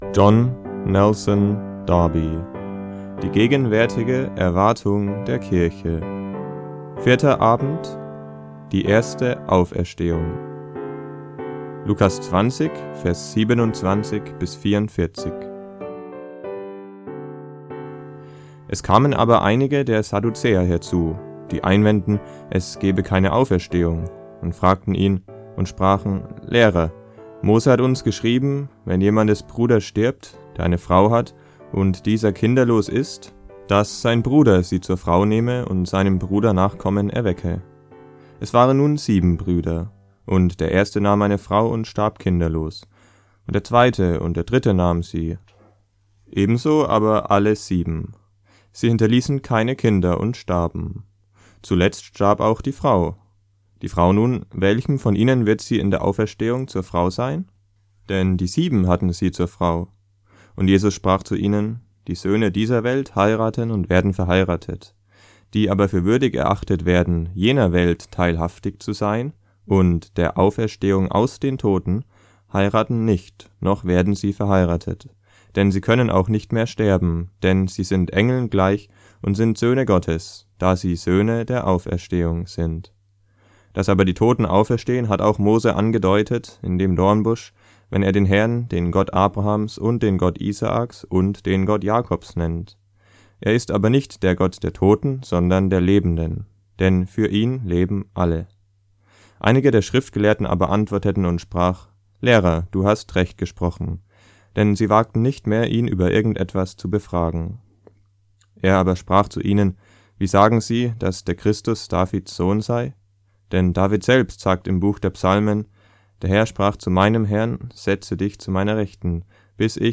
JND Hörbücher